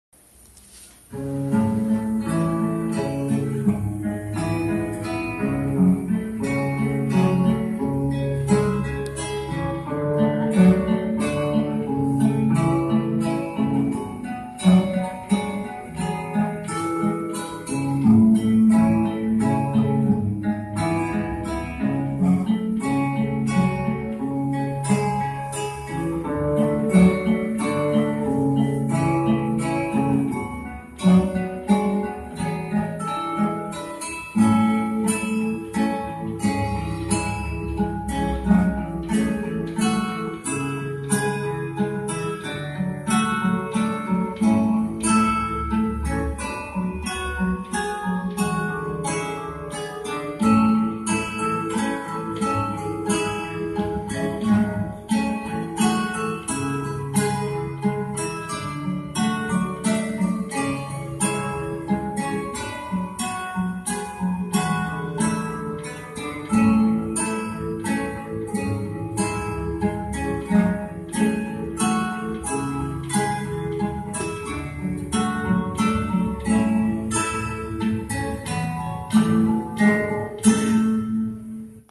Da Capo Acoustic Guitar Valsa n.1